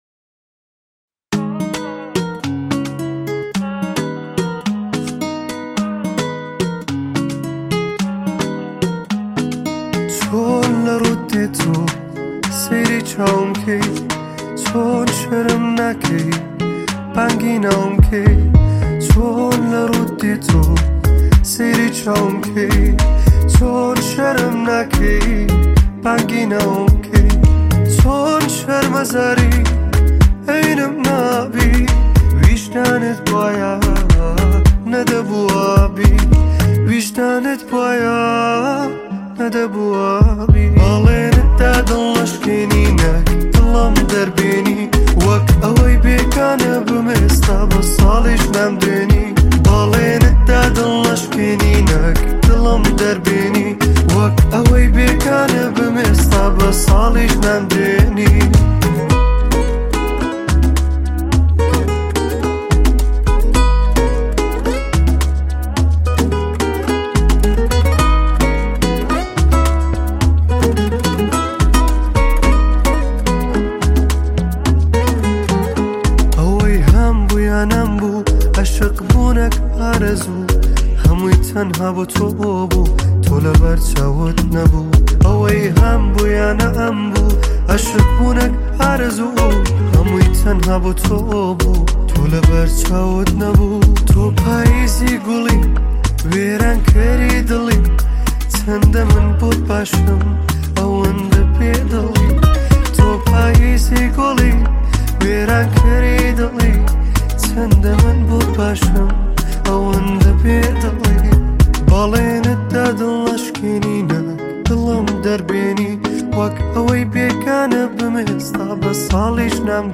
آهنگ کوردی